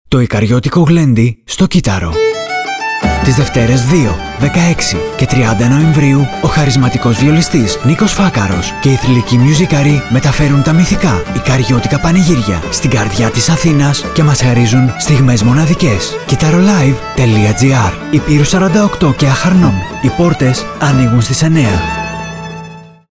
με χρώματα κι αρώματα από τα μυθικά πανηγύρια της Ικαρίας
Βιολί – Τσαμπούνα.
Λαούτο – Τραγούδι.
Κρουστά – Τραγούδι.
Μπουζούκι – Μπάσο.